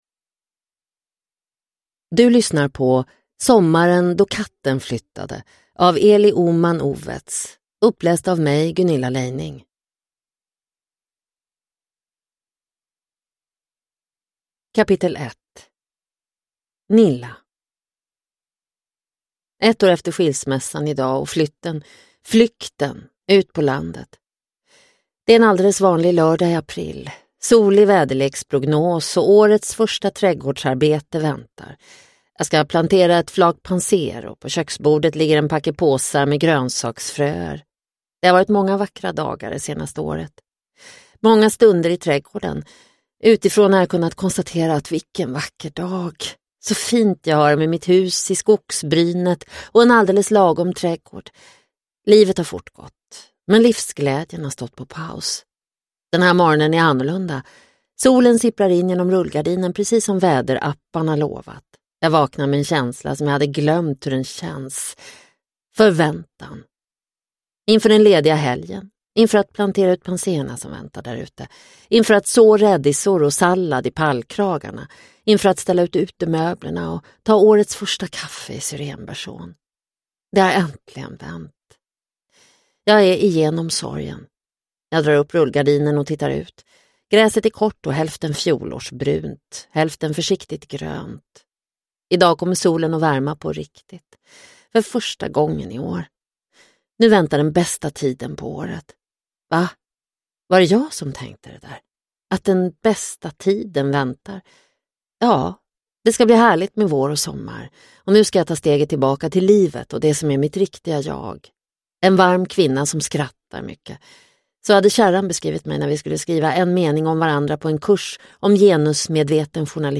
Sommaren då katten flyttade – Ljudbok – Laddas ner